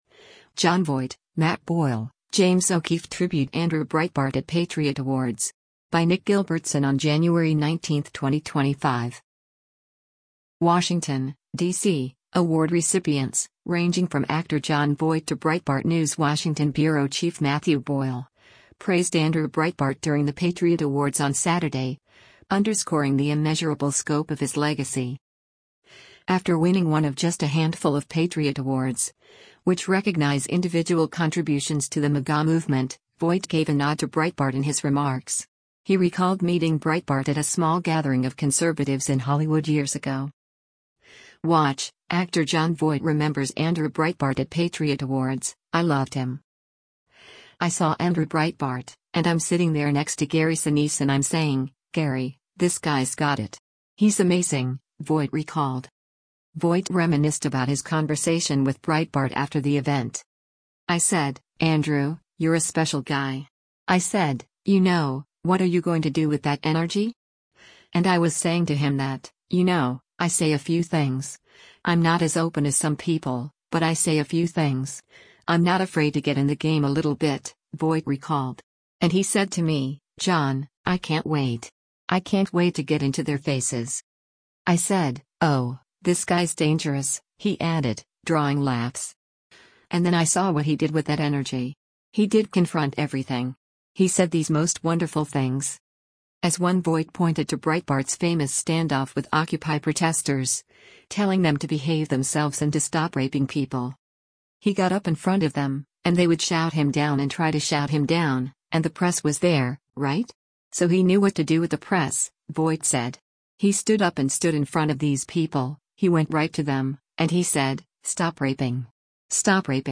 WATCH — Actor Jon Voight Remembers Andrew Breitbart at Patriot Awards: “I Loved Him”: